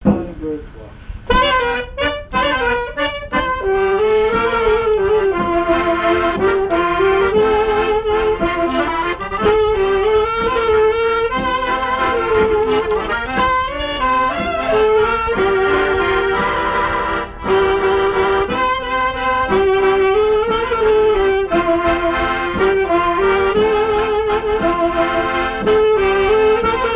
danse : valse
Genre strophique
Pièce musicale inédite